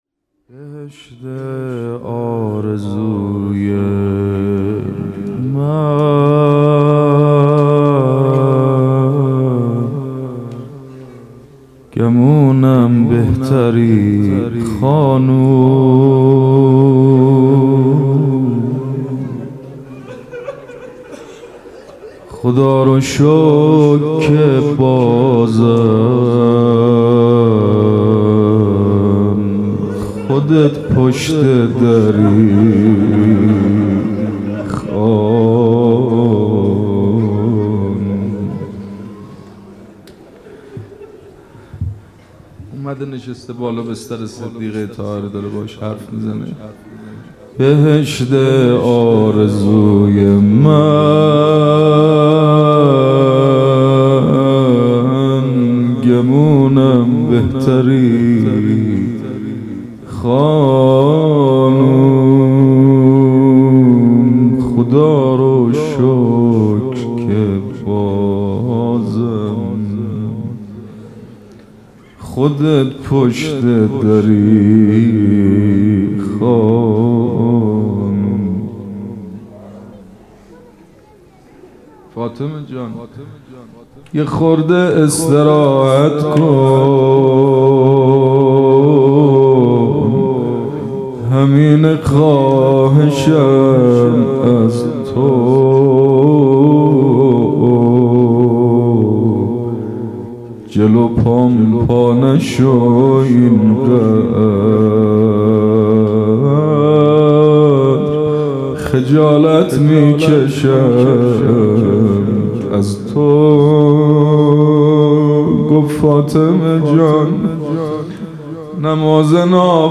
سخنرانی: نقش حکومت در انحراف مسلمین بعد از پیغمبر(ص)